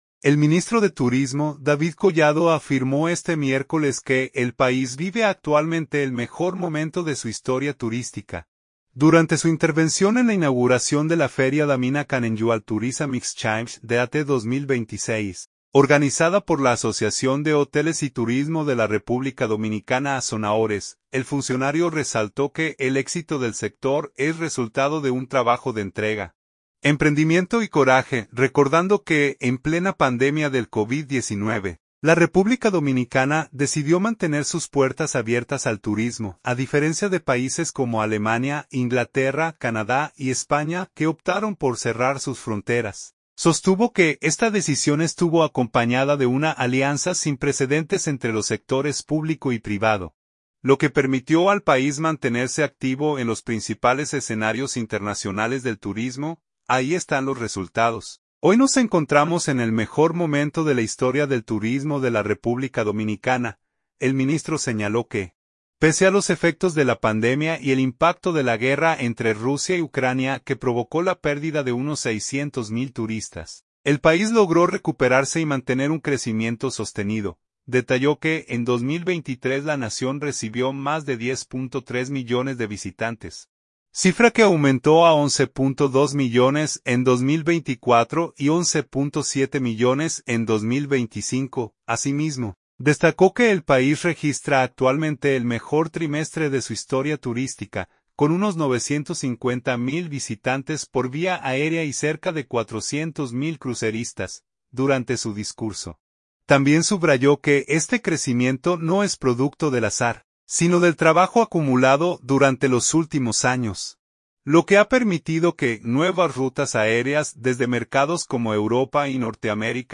Punta Cana. – El ministro de Turismo, David Collado afirmó este miércoles que el país vive actualmente el mejor momento de su historia turística, durante su intervención en la inauguración de la feria Dominican Annual Tourism Exchange (DATE) 2026, organizada por la Asociación de Hoteles y Turismo de la República Dominicana (Asonahores).